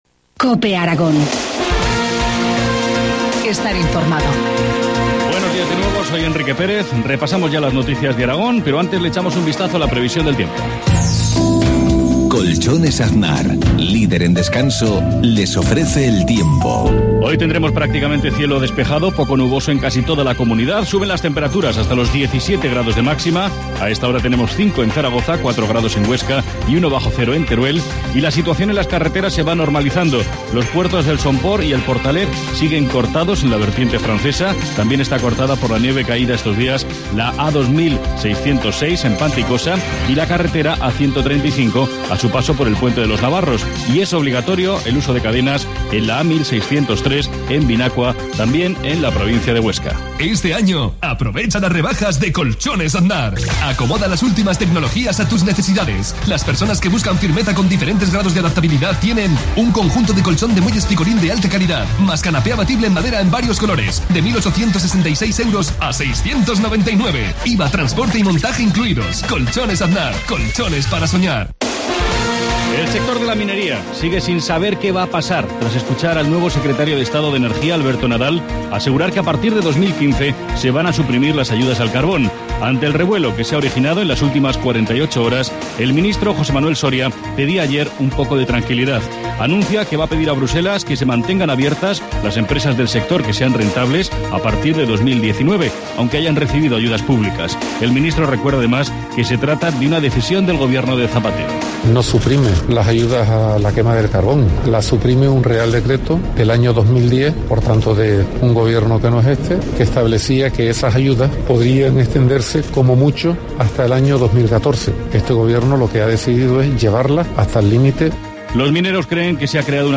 Informativo matinal, miércoles 30 de enero, 7.53 horas